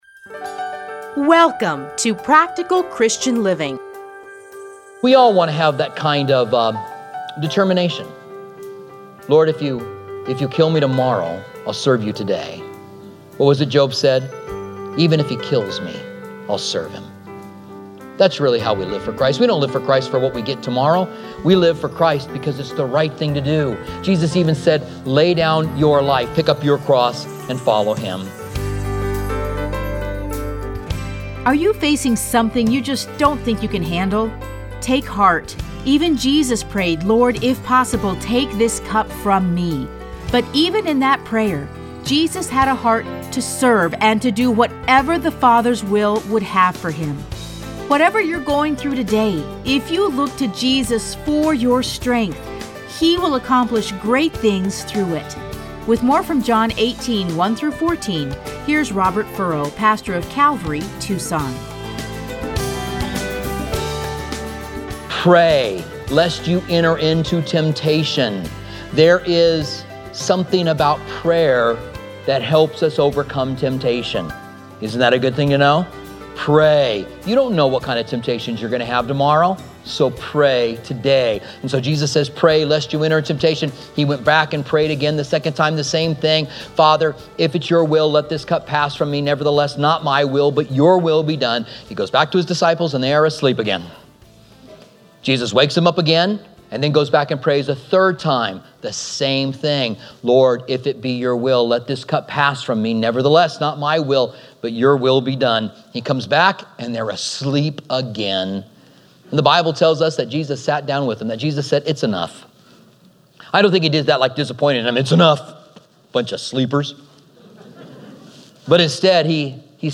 Listen to a teaching from John 18:1-14.